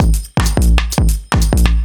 OTG_Kit6_Wonk_130b.wav